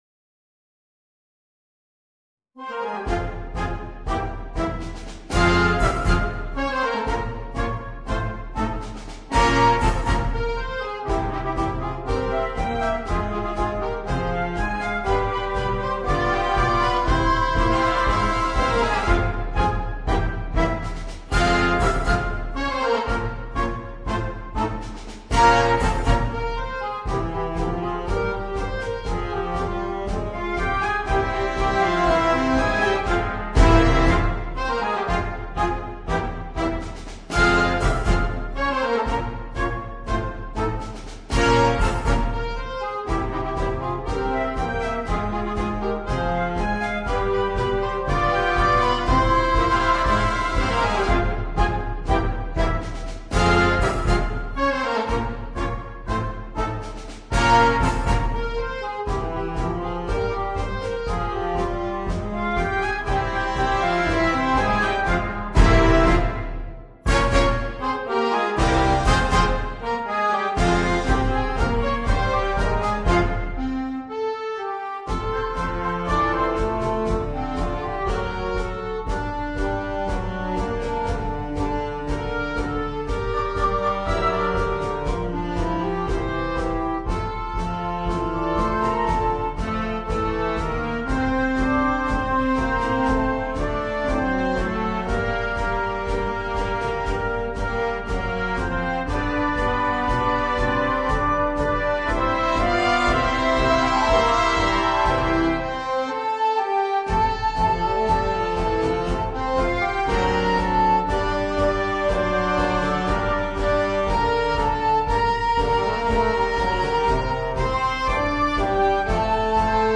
brano originale per banda